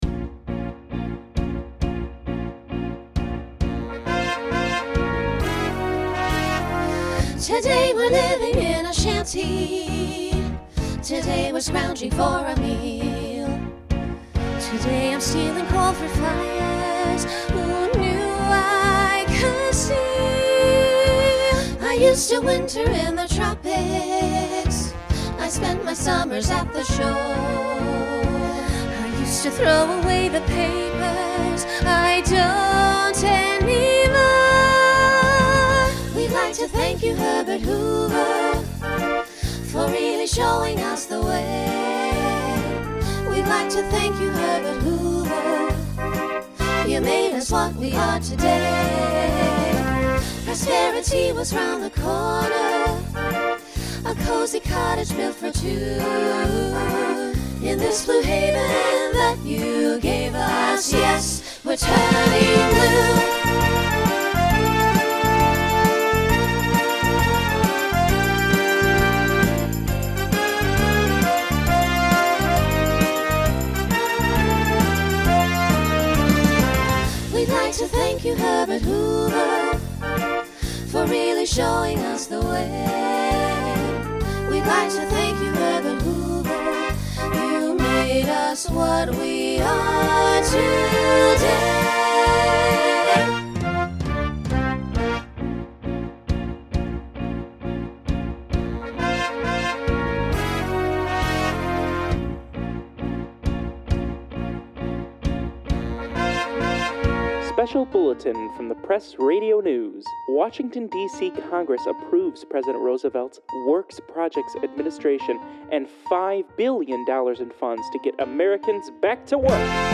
Pop/Dance
Transition Voicing Mixed